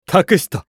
厨二病ボイス～戦闘ボイス～
【交代ボイス(戻)2】